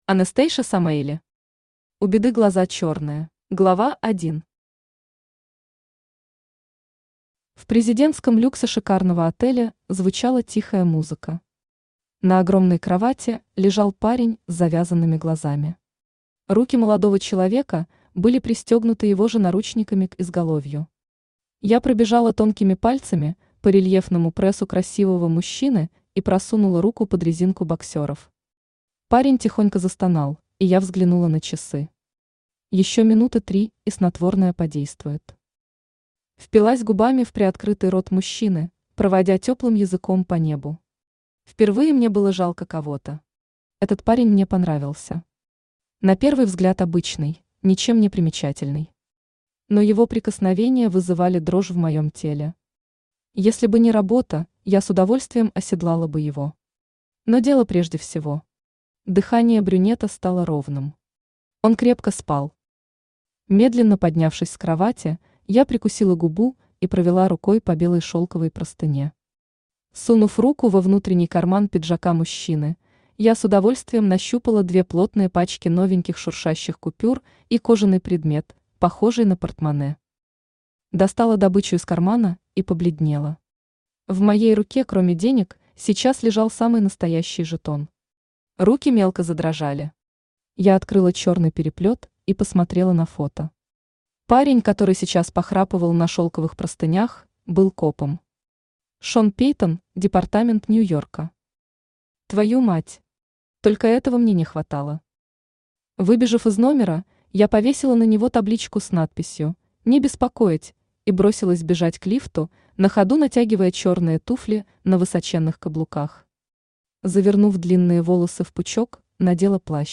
Аудиокнига У Беды глаза чёрные | Библиотека аудиокниг
Aудиокнига У Беды глаза чёрные Автор Anastasia Avi Samaeli Читает аудиокнигу Авточтец ЛитРес.